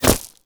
bullet_impact_gravel_03.wav